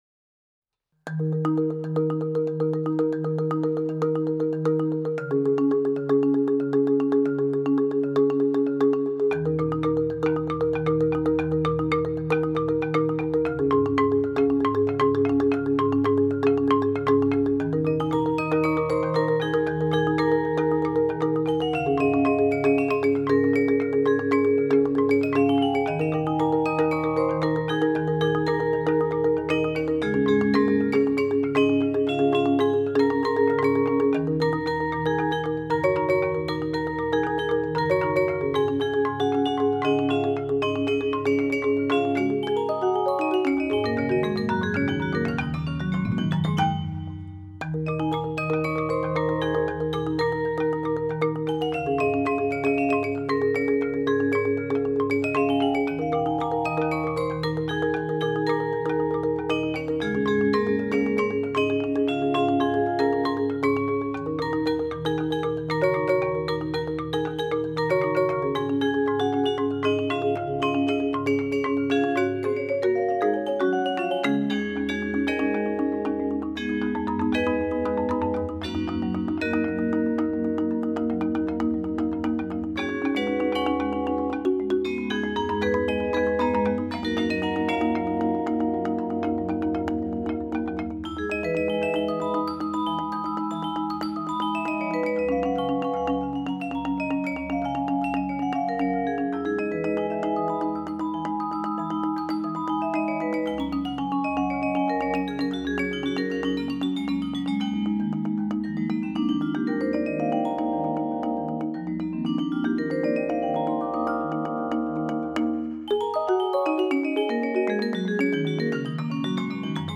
Trio für ein C-Marimba, Vibraphon und Drumset